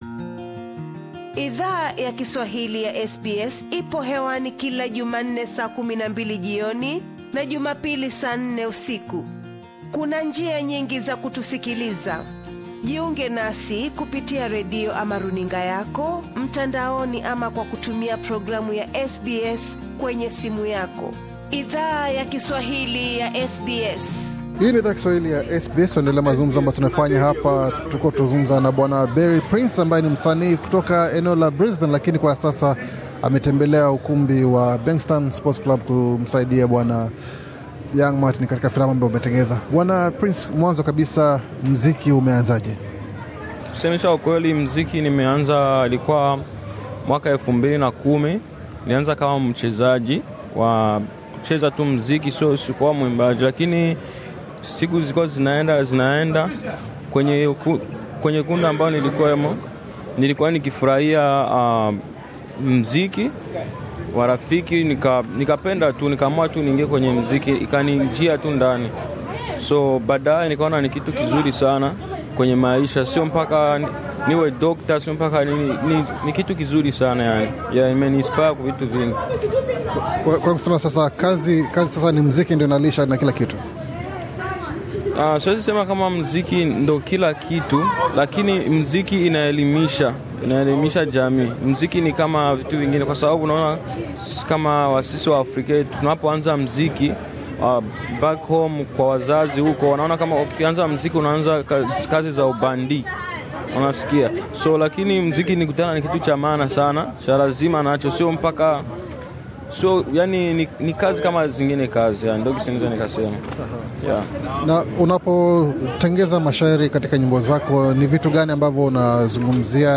Bofya hapo juu usikize mahojiano tuliyo fanya naye.